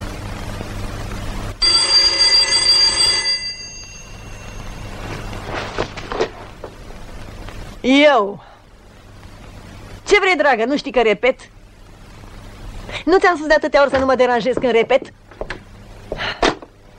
sunet-de-telefon-vechi-eu-ce-vrei-draga-nu-stii-ca-repet-nu-ti-am-spus-de-atatea-ori-sa-nu-ma-deranjezi-cand-repet-1.mp3